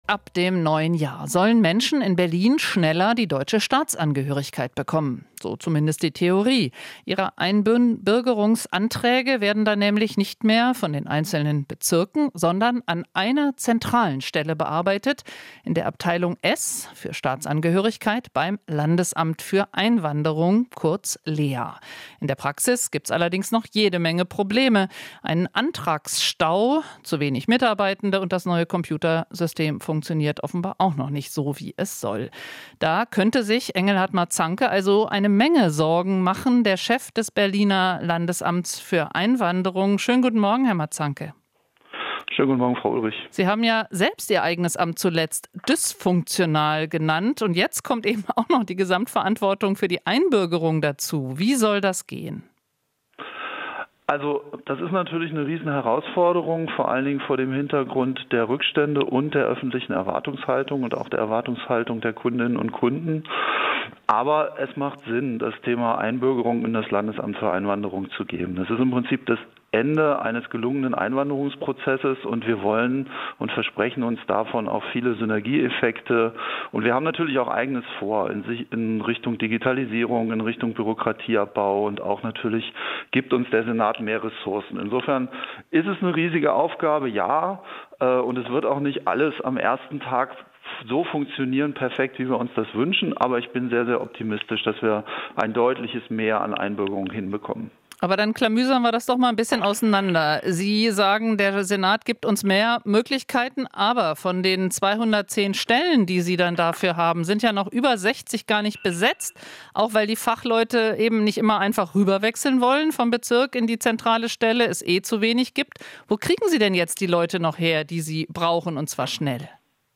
Interview - Landesamt für Einwanderung: Zentrale Einbürgerung ist "riesige Aufgabe"